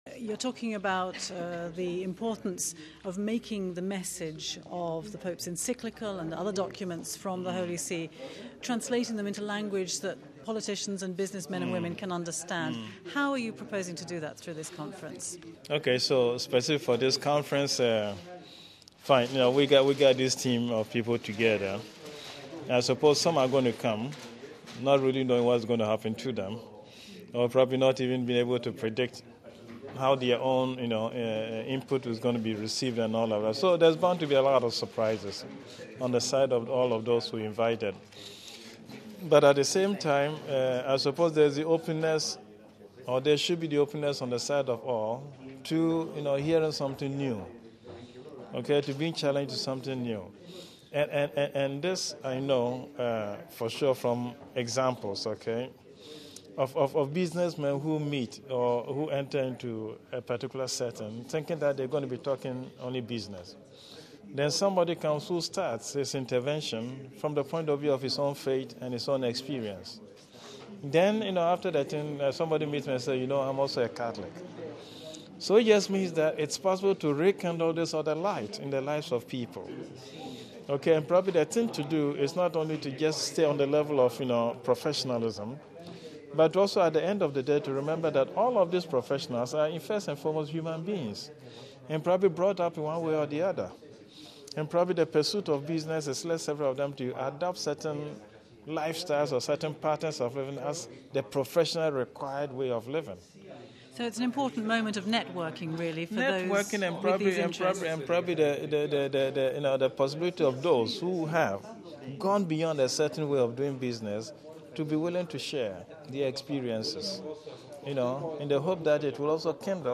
Cardinal Peter Turkson from Ghana is President of the Pontifical Justice and Peace Council